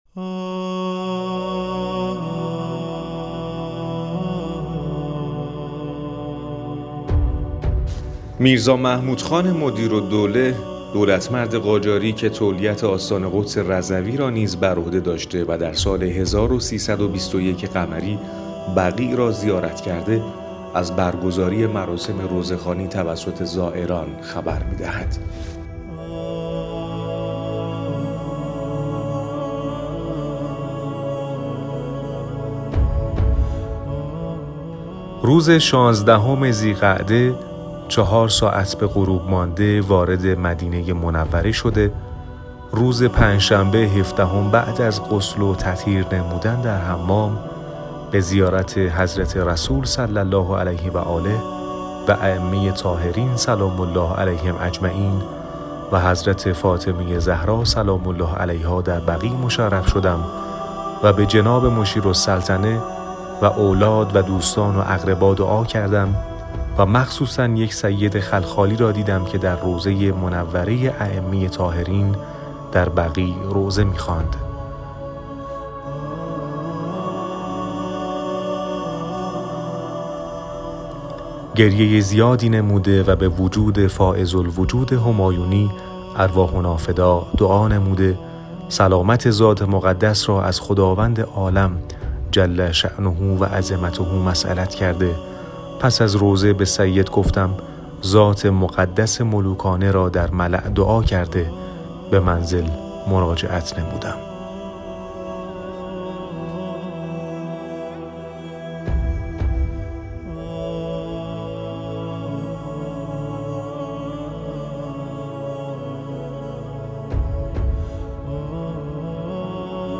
پنجشنبه/ پادکست | زیارتِ بقیع